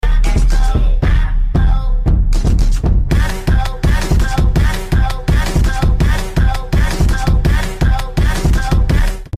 NAHH sound effects free download
You Just Search Sound Effects And Download. tiktok comedy sound effects mp3 download Download Sound Effect Home